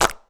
07_Clap_09_SP.wav